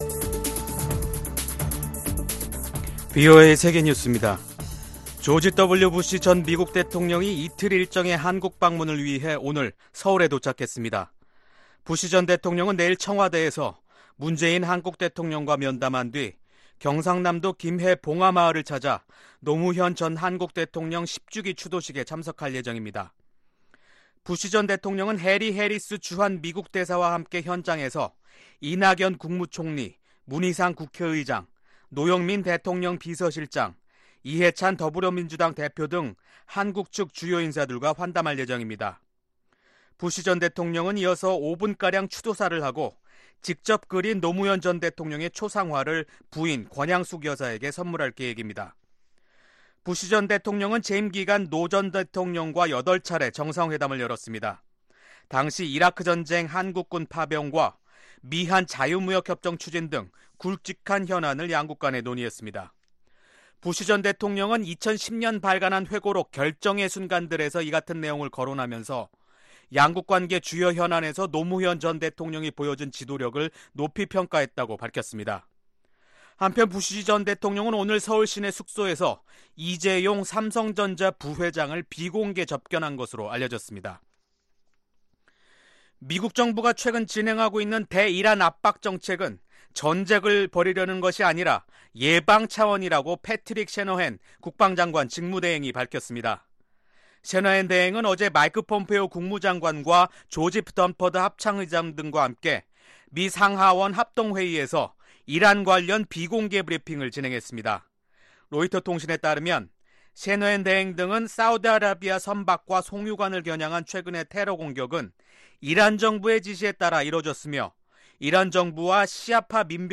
VOA 한국어 간판 뉴스 프로그램 '뉴스 투데이', 2019년 5월 22일 3부 방송입니다. 올해 상반기 미국 의회에서 발의된 한반도 외교 안보 관련 법안과 결의안이 총 10건으로 북한 문제에 대한 미 의회의 지속적인 관심이 이어지고 있습니다. 유엔주재 김성 북한대사가 미국의 화물선 ‘와이즈 어네스트호’ 압류가 부당하다며 즉각적인 반환을 요구했습니다.